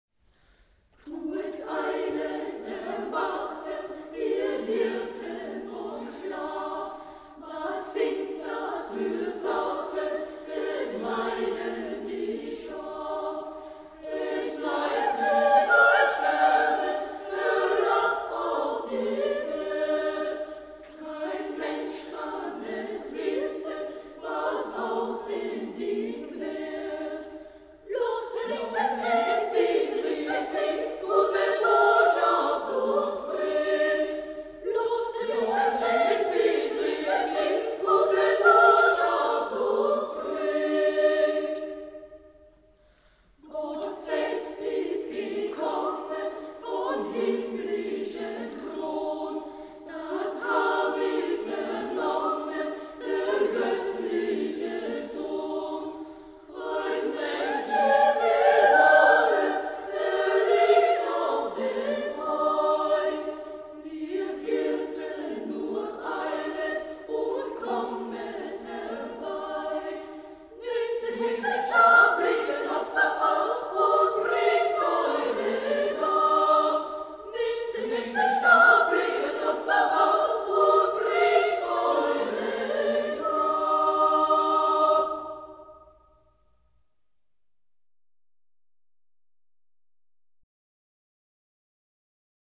Hirtenterzett (MP3, 3.087 KB)
04 Hirtenterzett.mp3